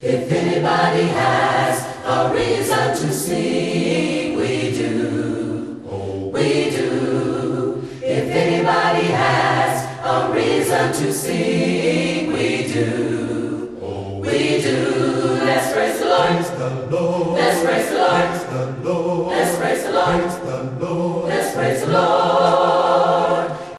Your source for the best in A'cappella Christian Vusic ®